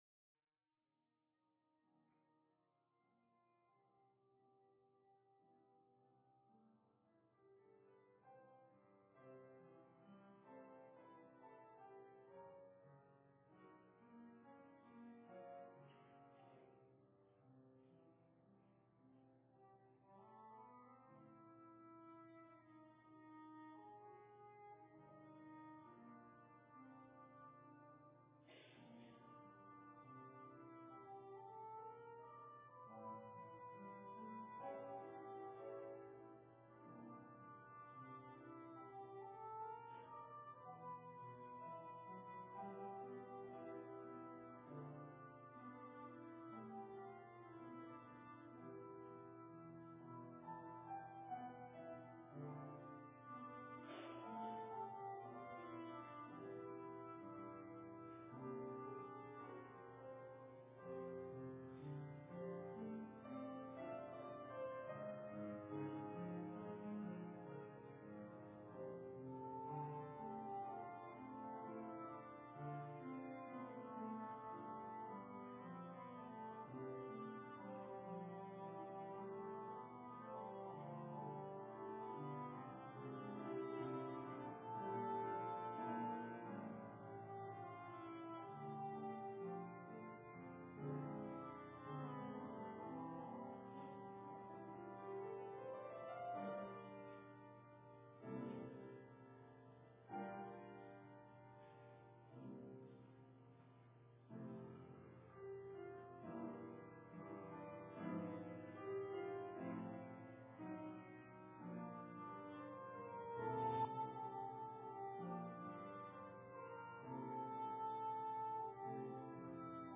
Minnetonka Livestream · Friday, April 15, 2022 7:00 pm
Sermon